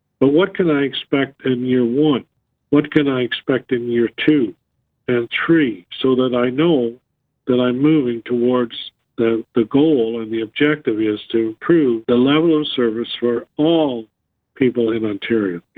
Quinte West Mayor Jim Harrison said the progress on the Cell Gap project is good but he wants to know when results can be expected.